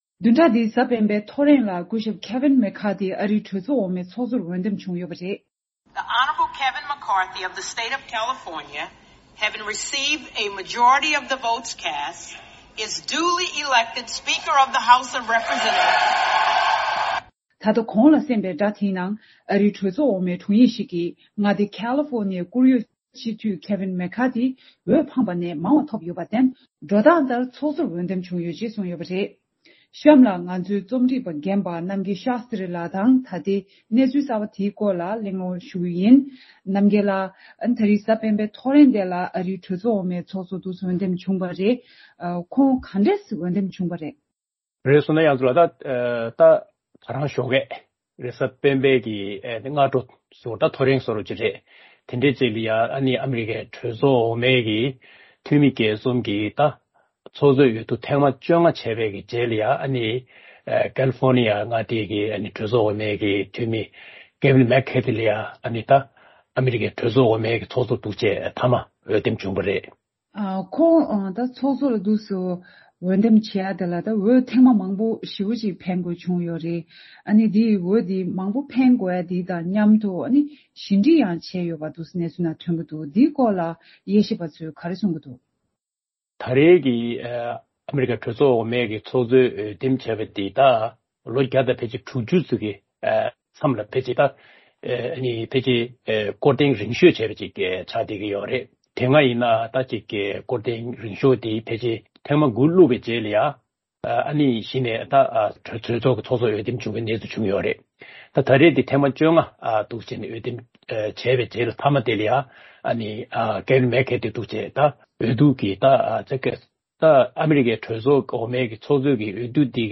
བཅར་འདྲི་ཞུས་ཡོད།